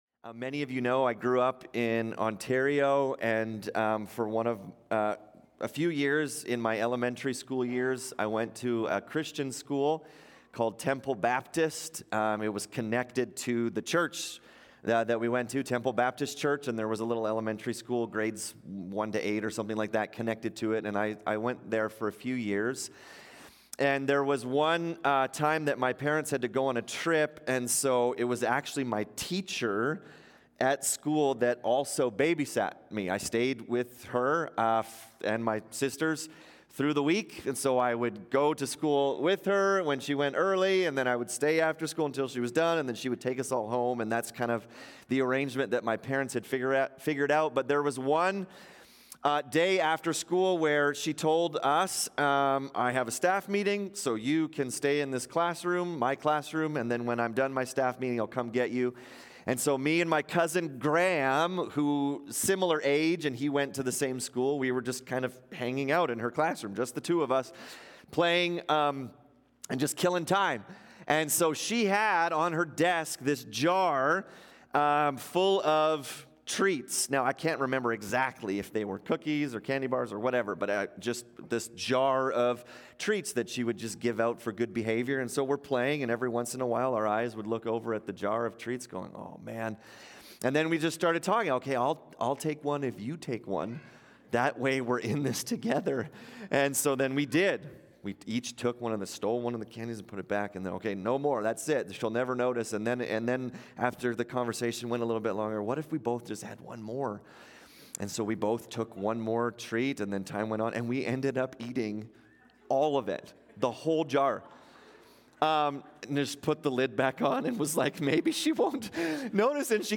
In this sermon we look at the 8th Commandment: You shall not steal. What does this command actually mean and how do we break it today?